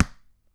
ball_bounce.wav